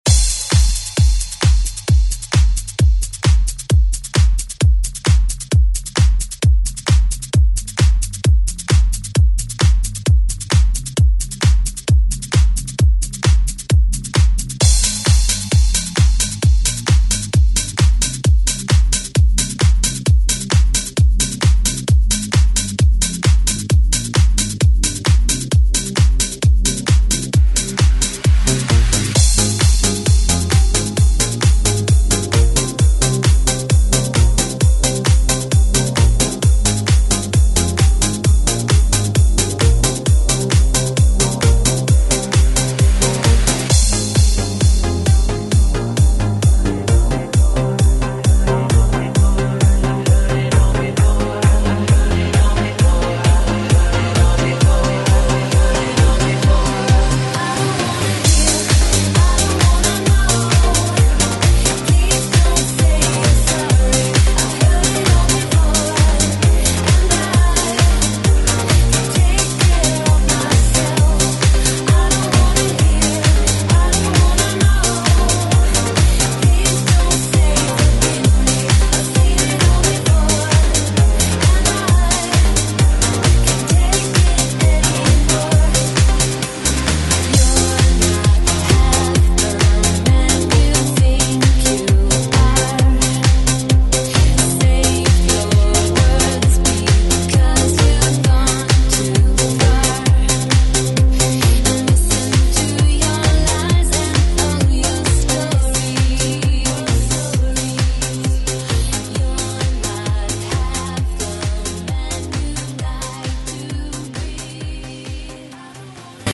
Electronic Euro Pop Music Extended Club ReWork Clean 115 bpm
Genres: 90's , DANCE , RE-DRUM
Clean BPM: 115 Time